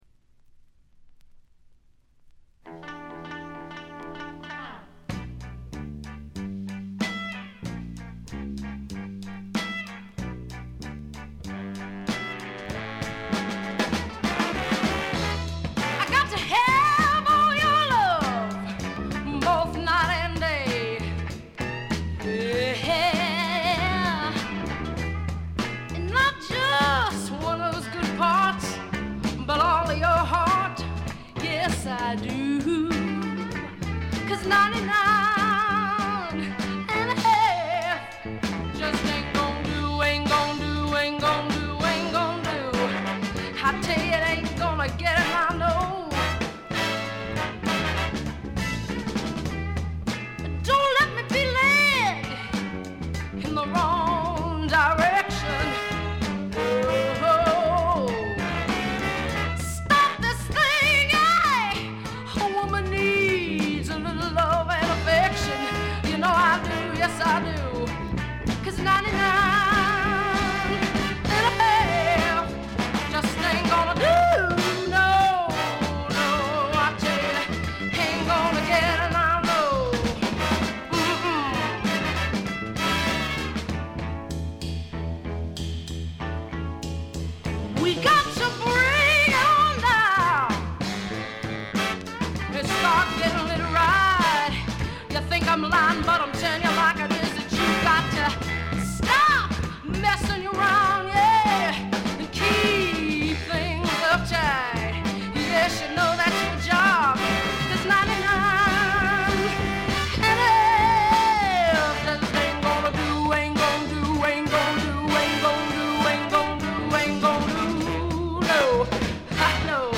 鑑賞を妨げるほどのノイズはありません。
試聴曲は現品からの取り込み音源です。